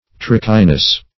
Trickiness \Trick"i*ness\, n.